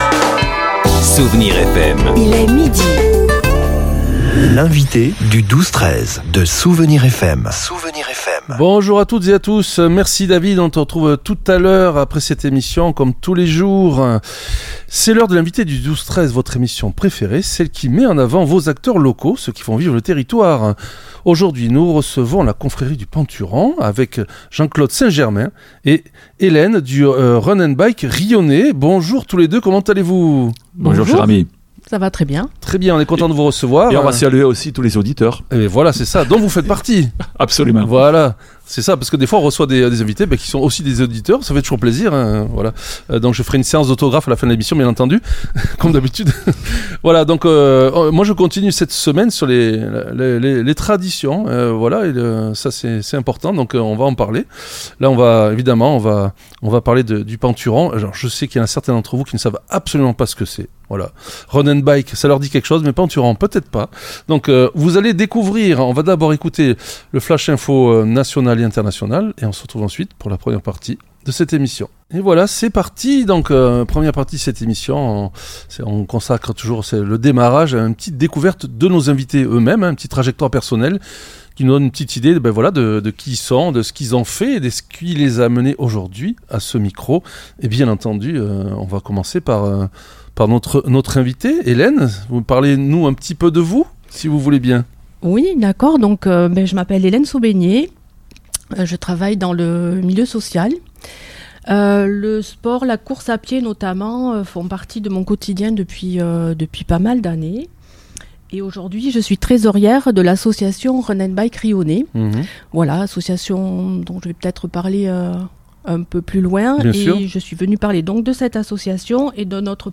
L'entretien a permis de dévoiler un calendrier riche en collaborations.